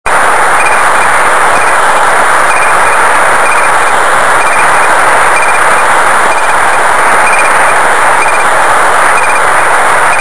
Beacon CW Oscar1